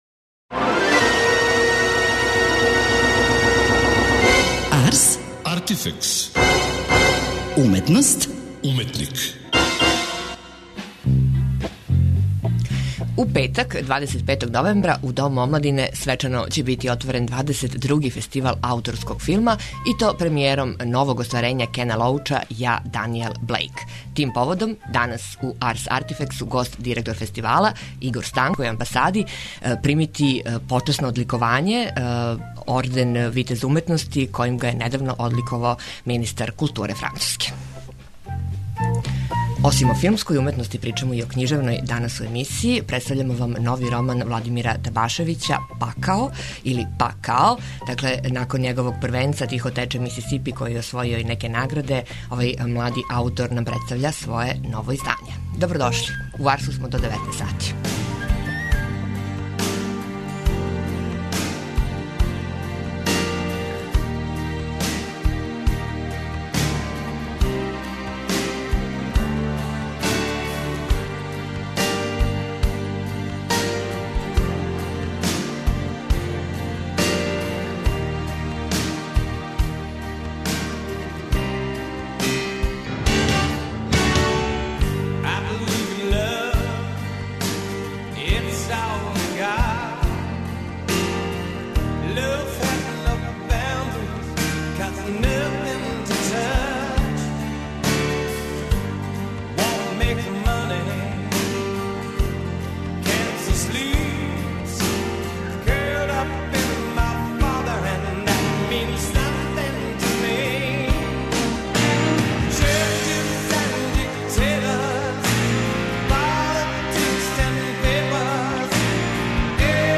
преузми : 27.93 MB Ars, Artifex Autor: Београд 202 Ars, artifex најављује, прати, коментарише ars/уметност и artifex/уметника.